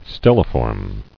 [stel·li·form]